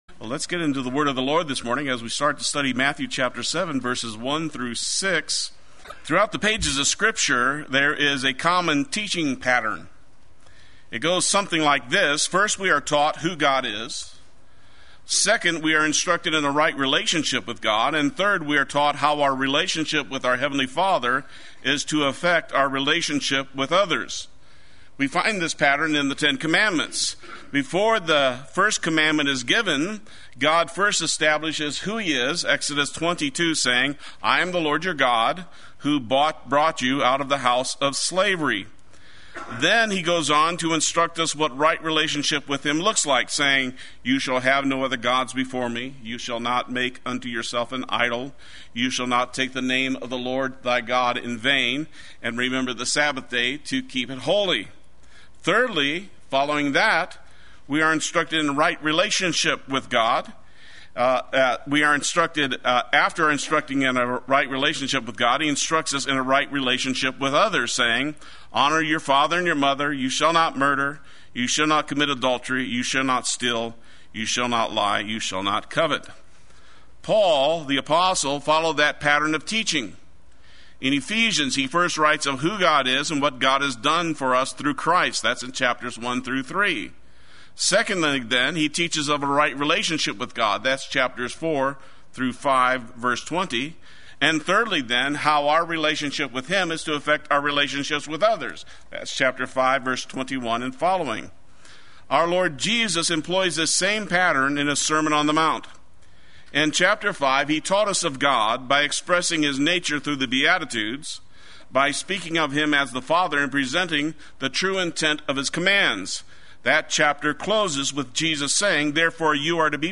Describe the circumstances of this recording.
Make Proper Judgement Sunday Worship